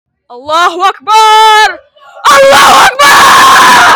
allahu akbaaaar Meme Sound Effect
allahu akbaaaar.mp3